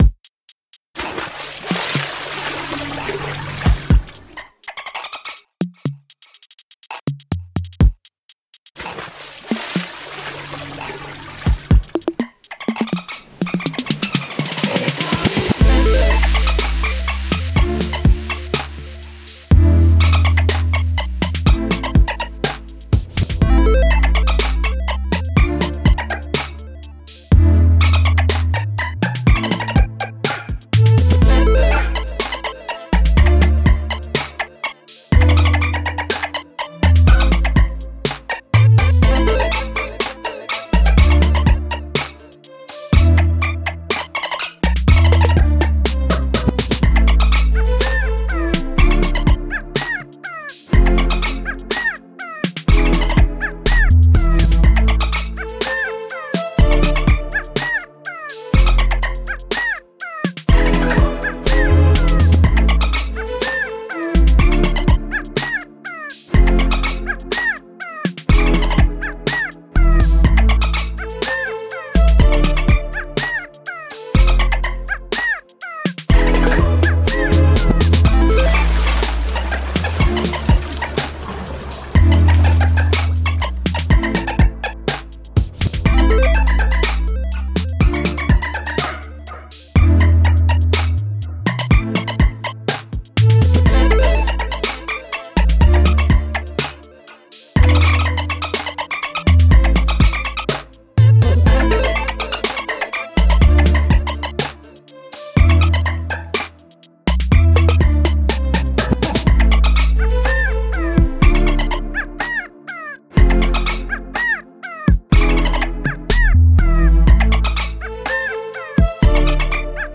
It's a beat at 120 BPM in A miner, featuring chopped samples from the iconic Flipper the Dolphin sound from the 1960s cartoon, Flipper.
Also featuring the call of a quale, as per the 1995 Compyom's Interactive Encyclopedia, because it's a fun noise.
Interestingly, by original Ableton Move broke while I was making this, so I didn't get to finish it until I got the replacement unit.
I have posted this before, but this is a slightly different mix with a couple of bugs fixed and new ones added.